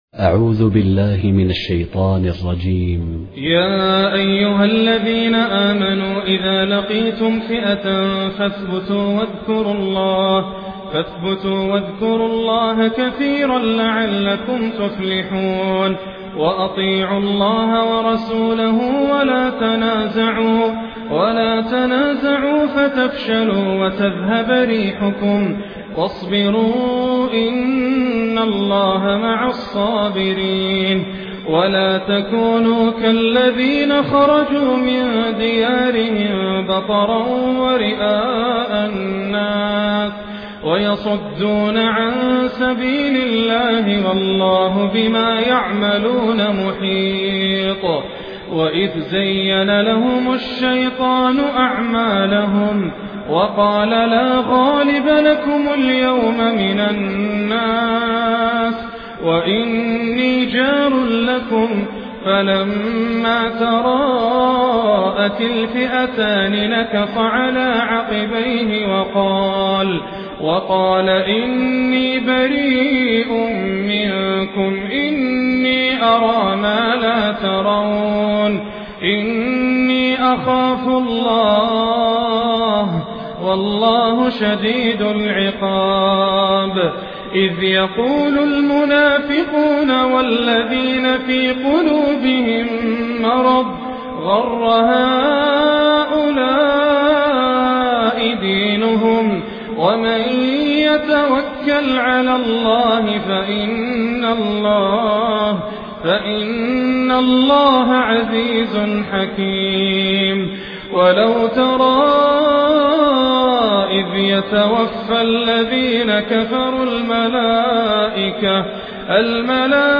Quran recitations
Humble, distinctive recitations